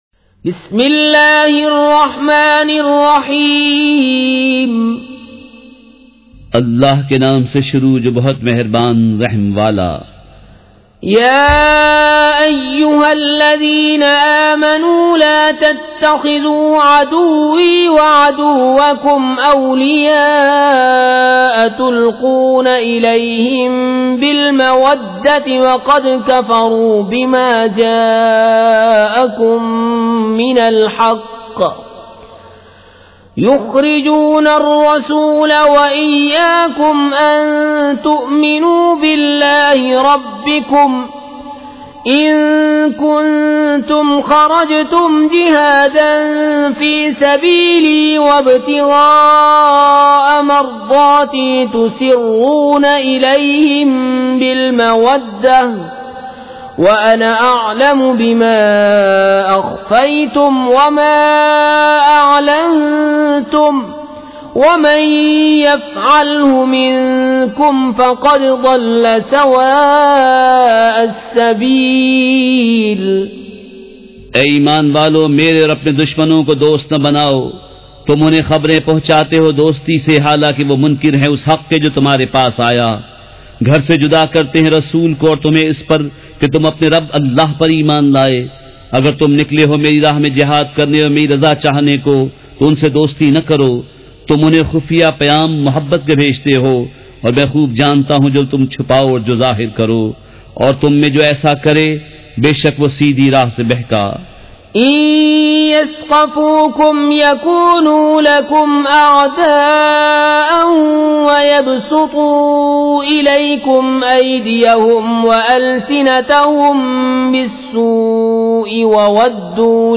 سورۃ الممتحنۃ مع ترجمہ کنزالایمان ZiaeTaiba Audio میڈیا کی معلومات نام سورۃ الممتحنۃ مع ترجمہ کنزالایمان موضوع تلاوت آواز دیگر زبان عربی کل نتائج 1503 قسم آڈیو ڈاؤن لوڈ MP 3 ڈاؤن لوڈ MP 4 متعلقہ تجویزوآراء